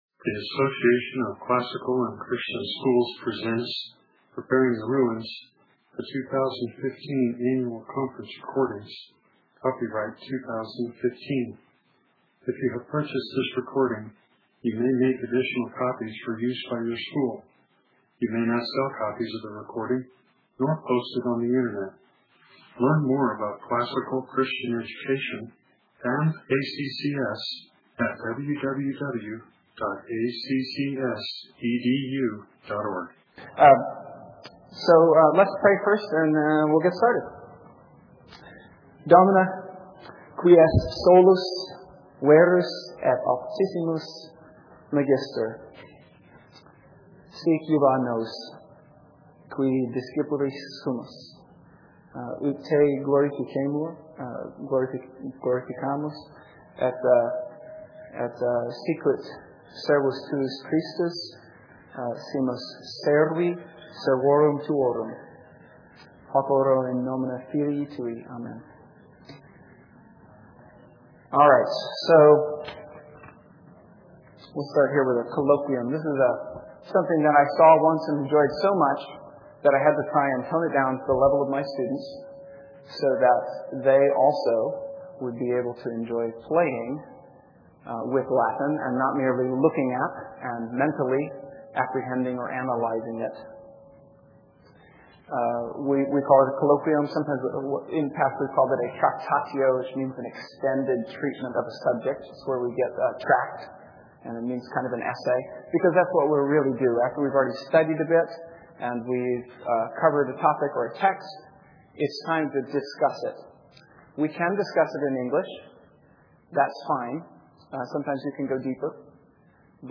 2015 Workshop Talk | 1:03:16 | All Grade Levels, Latin, Greek & Language
In this workshop session, attendees will have an opportunity to participate in a mini-colloquium.
A question and answer time will follow.